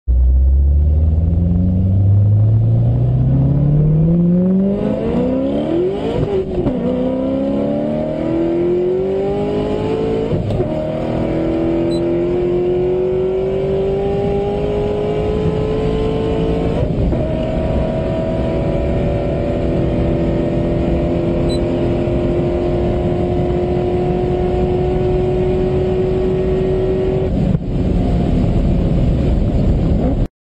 Car Acceleration Sound FX Please sound effects free download
#8DSound